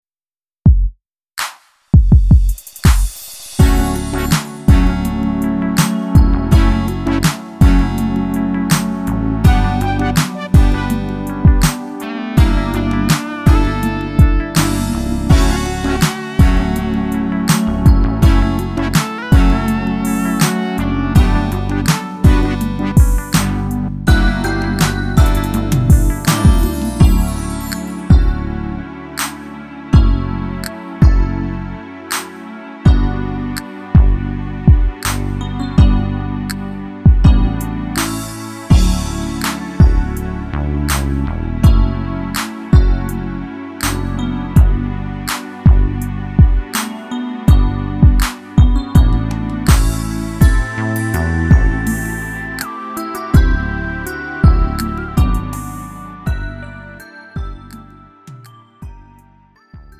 음정 원키 4:07
장르 가요 구분